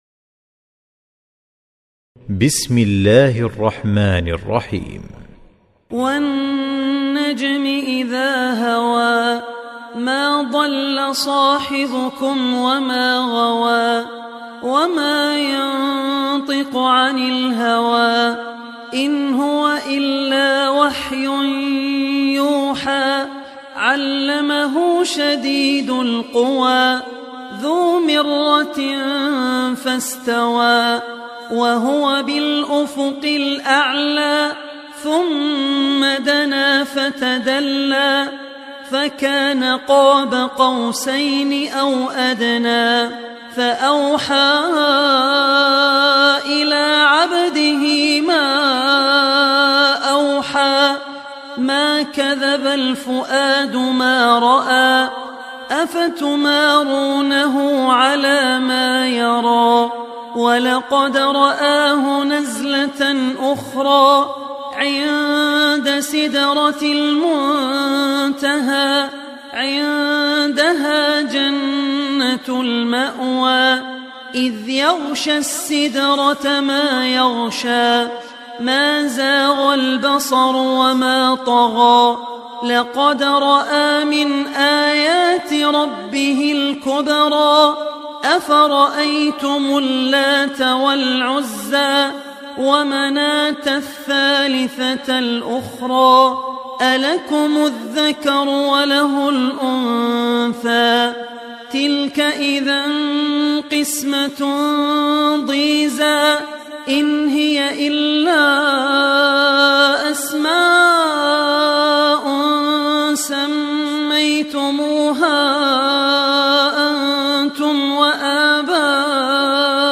Surah An Najm Beautiful Recitation MP3 Download By Abdul Rahman Al Ossi in best audio quality.